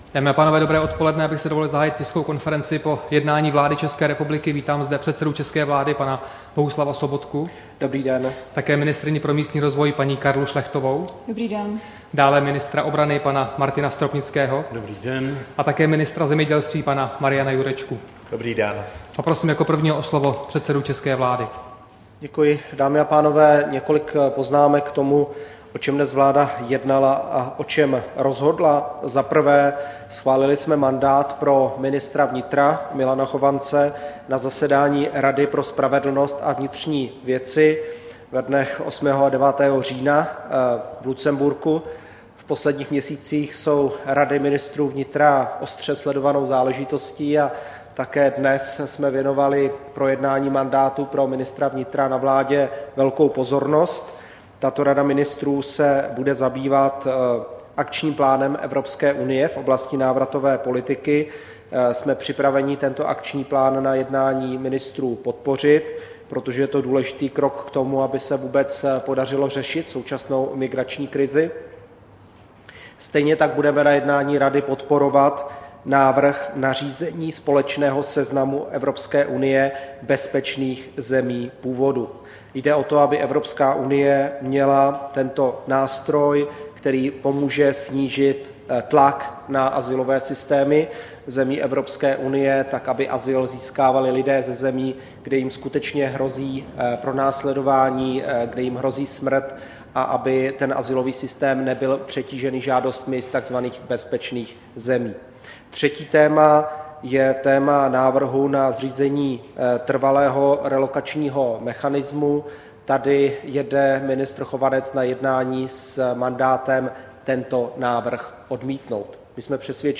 Tisková konference po jednání vlády 5. října 2015